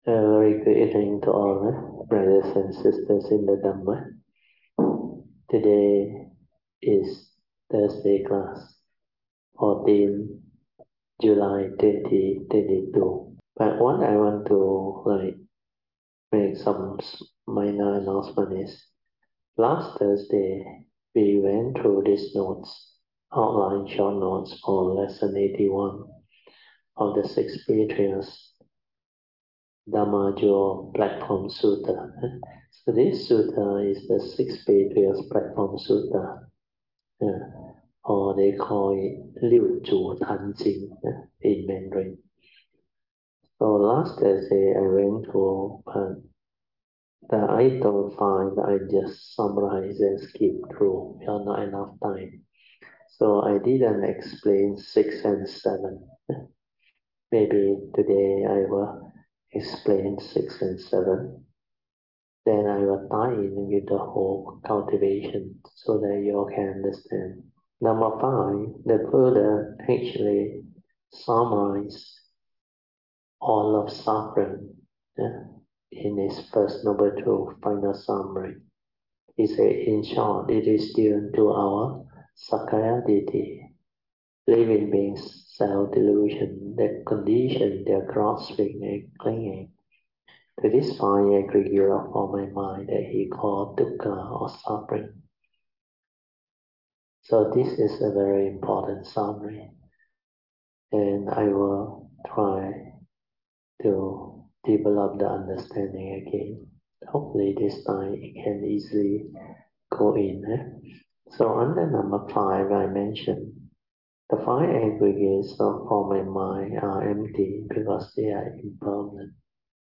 Thursday Class